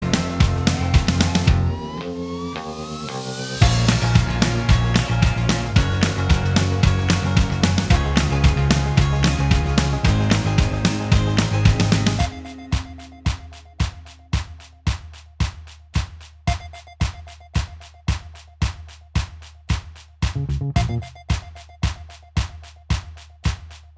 Minus Main Guitar Pop (2010s) 3:34 Buy £1.50